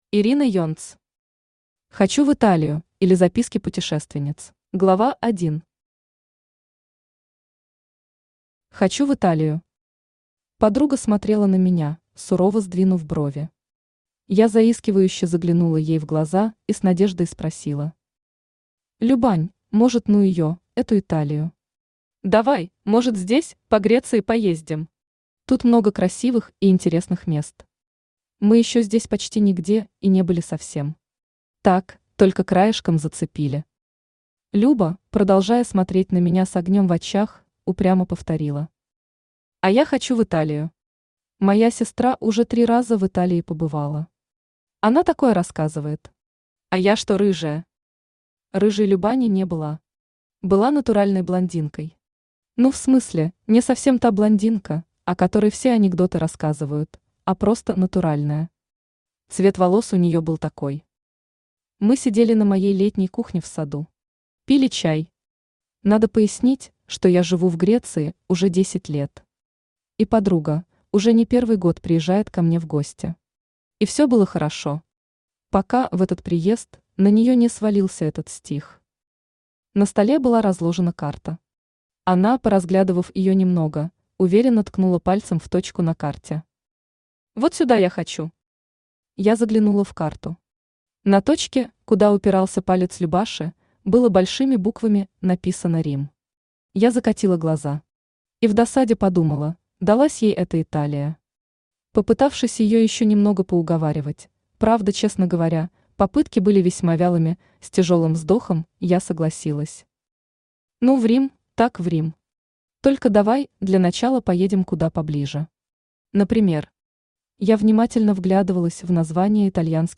Аудиокнига Хочу в Италию, или Записки путешественниц | Библиотека аудиокниг
Aудиокнига Хочу в Италию, или Записки путешественниц Автор Ирина Юльевна Енц Читает аудиокнигу Авточтец ЛитРес.